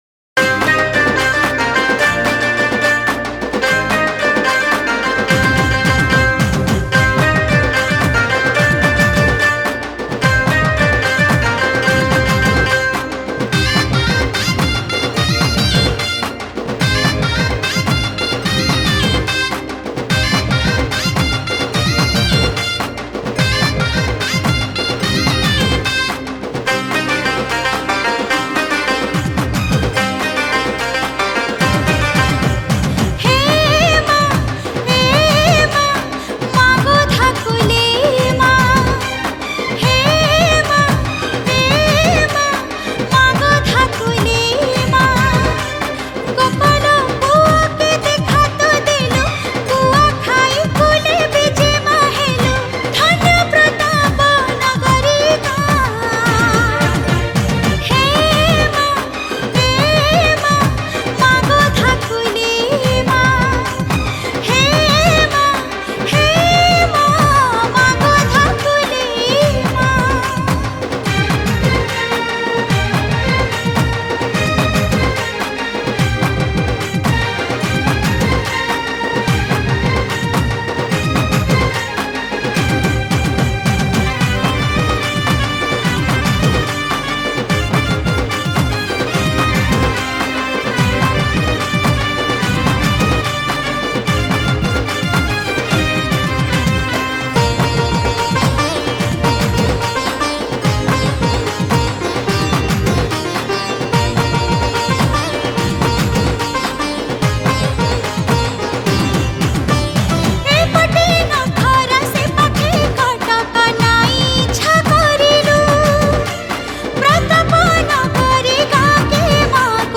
Odia Bhajan Song 2022 Songs Download